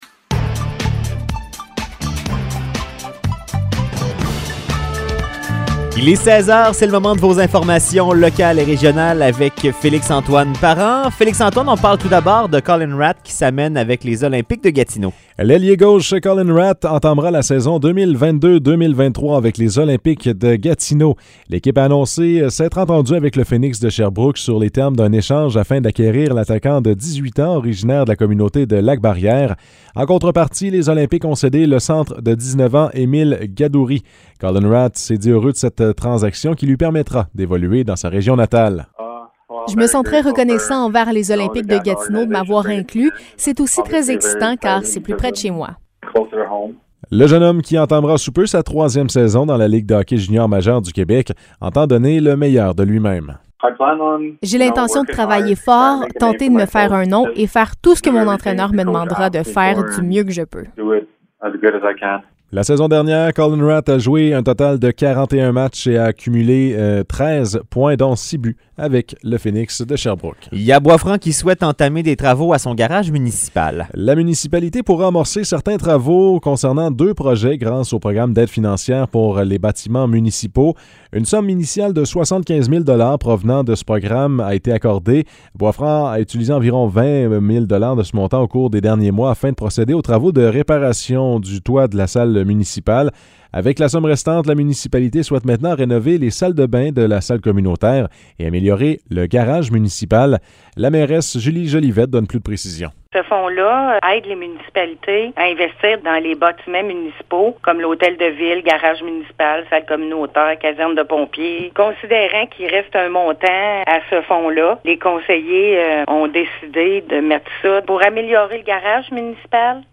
Nouvelles locales - 30 août 2022 - 16 h